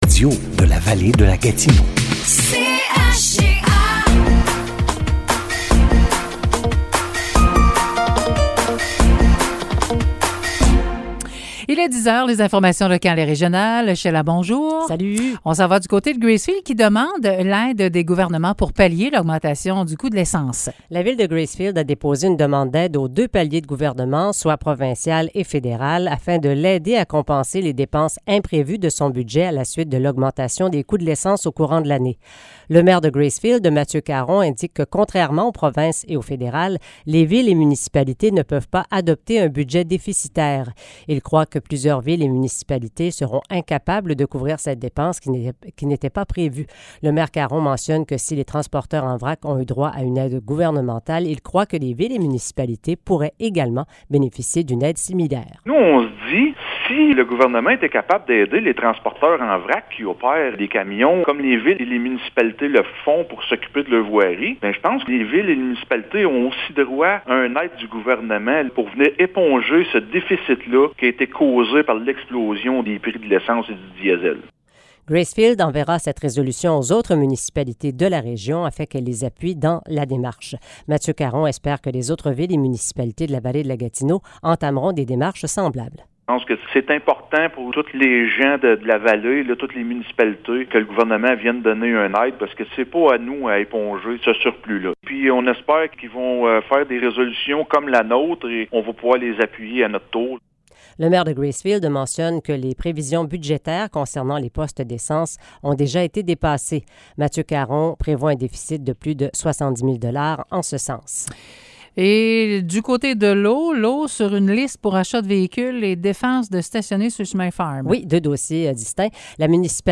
Nouvelles locales - 27 septembre 2022 - 10 h